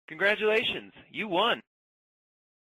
congrats.mp3